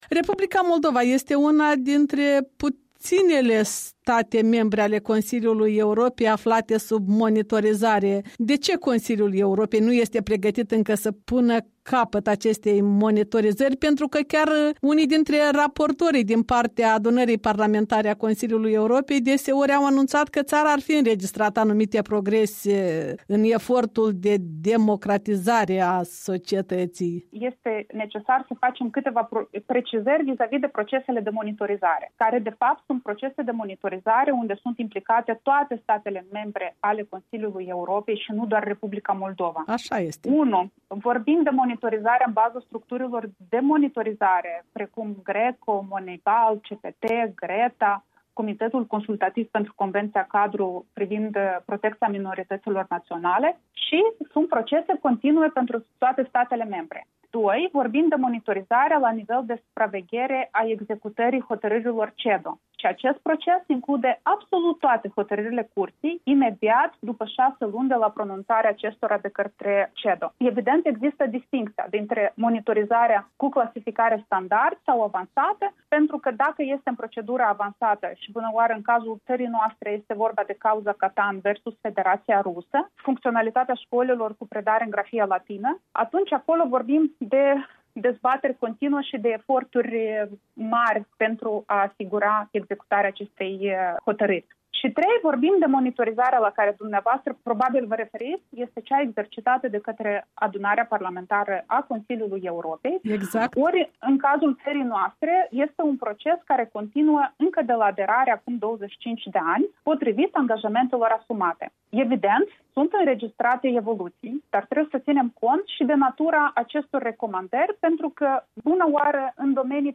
O convorbire cu Reprezentantul Permanent al Republicii Moldova pe lângă Consiliul Europei, Corina Călugăru.